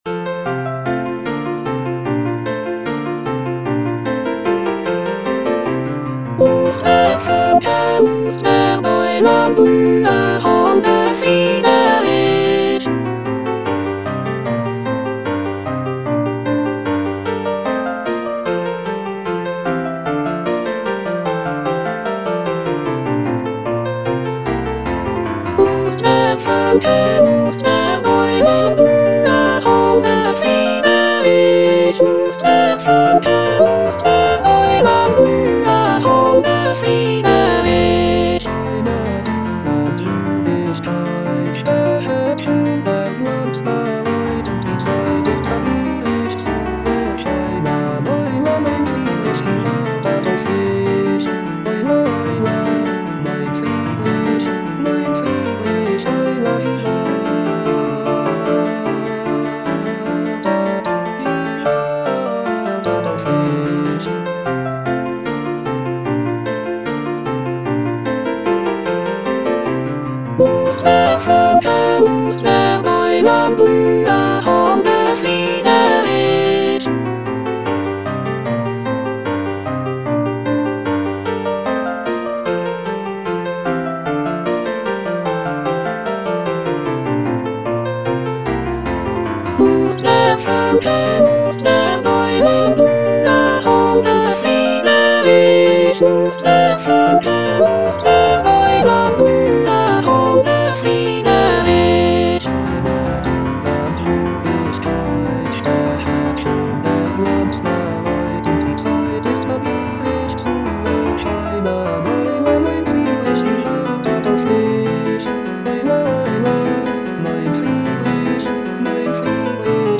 Choralplayer playing Cantata
Soprano (Emphasised voice and other voices) Ads stop